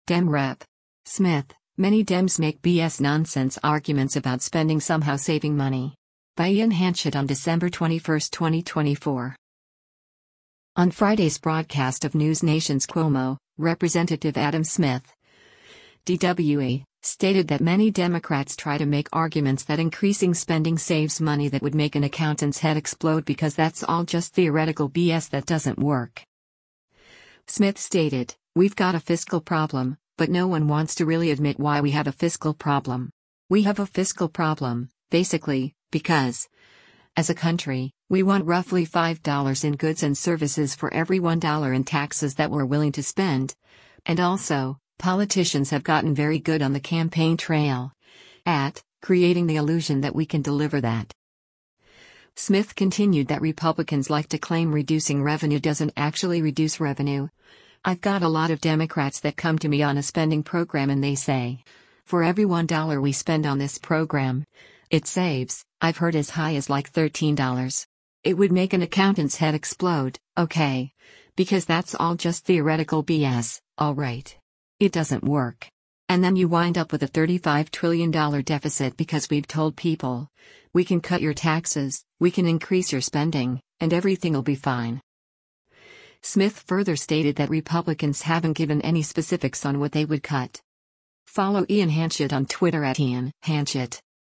On Friday’s broadcast of NewsNation’s “Cuomo,” Rep. Adam Smith (D-WA) stated that many Democrats try to make arguments that increasing spending saves money that “would make an accountant’s head explode” “because that’s all just theoretical B.S.” that “doesn’t work.”